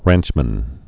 (rănchmən)